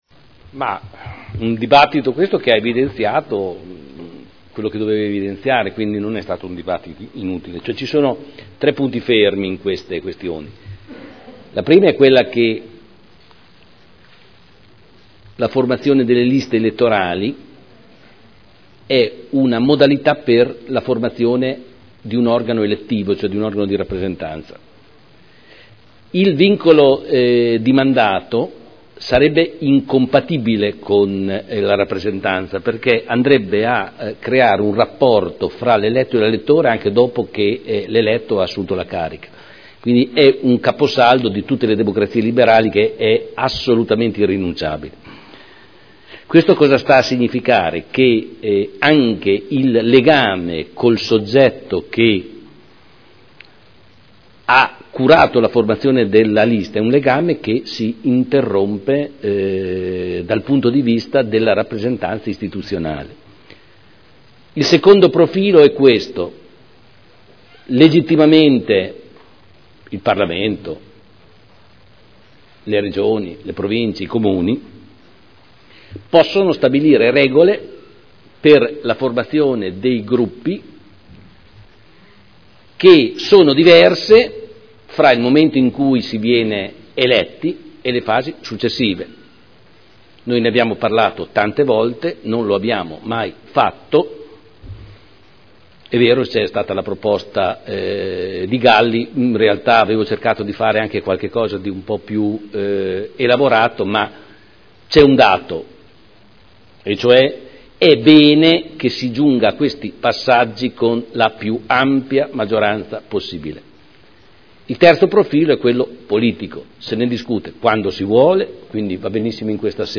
Seduta del 12 dicembre Commissione consiliare permanente Programmazione e Assetto del Territorio, Sviluppo economico e Tutela ambientale – Modifica Dibattito